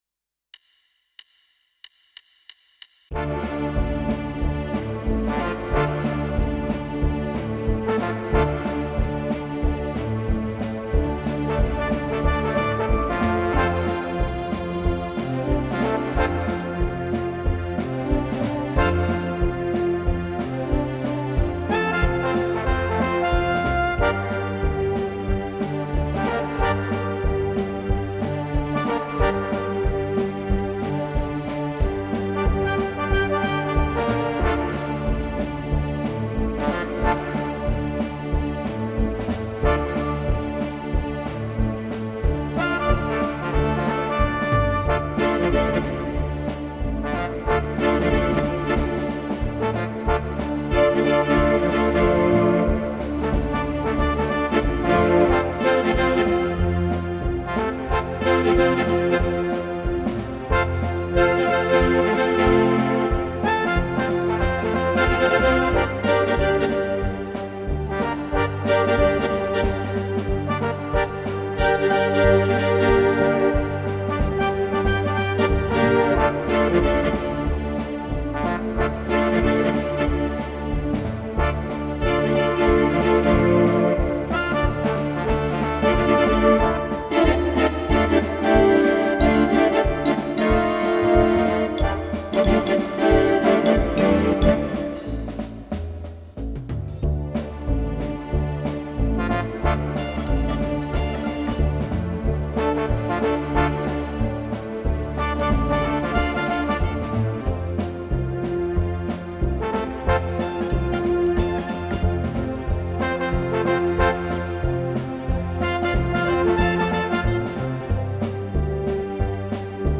Home > Music > Jazz > Bright > Medium > Laid Back